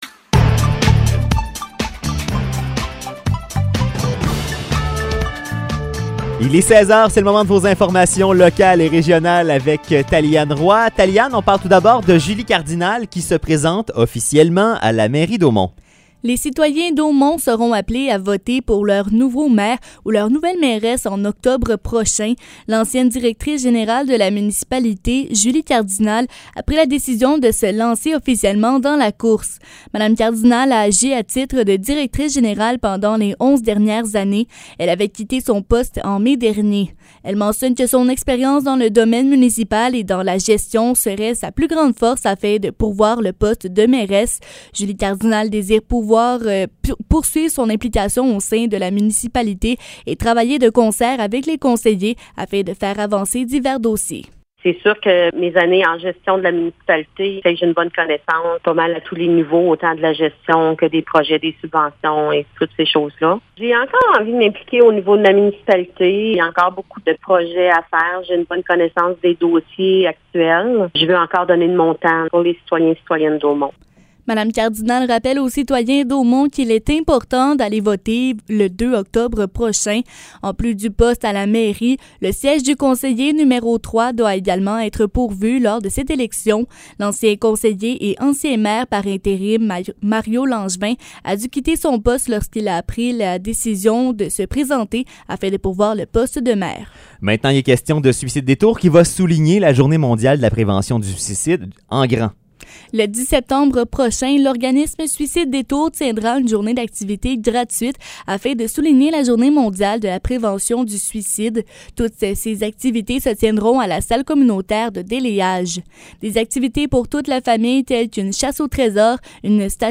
Nouvelles locales - 24 août 2022 - 16 h